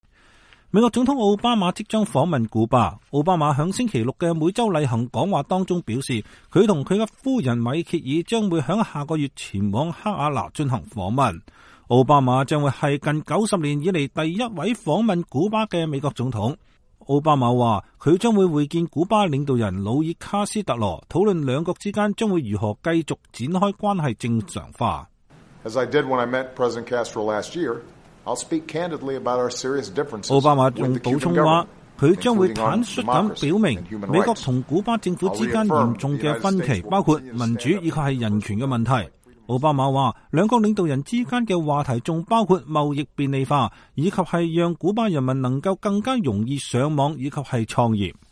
奧巴馬星期六在每週例行講話中說，他和夫人米歇爾將於下月前往哈瓦那進行訪問。